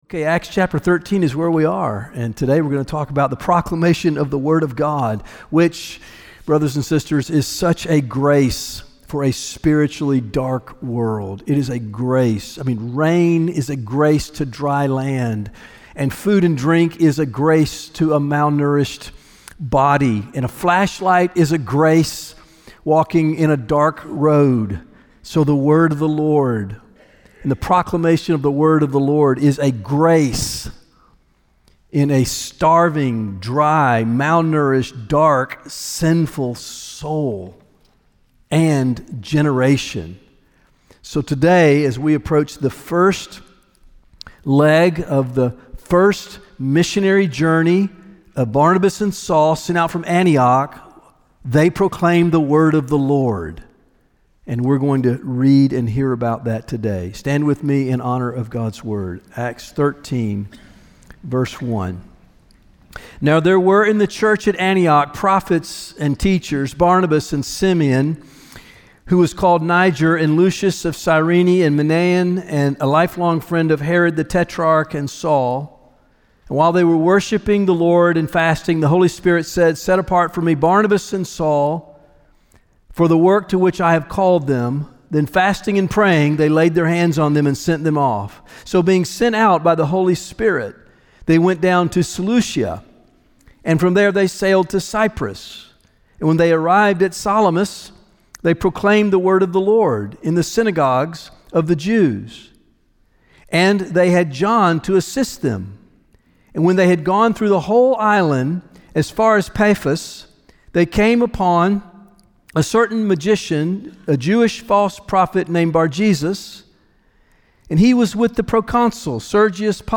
One of the ways that we pursue this mission is by gathering each Sunday for corporate worship, prayer, and biblical teaching.